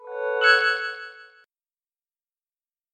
Звуки включения устройств
Звук включения мультимедийного устройства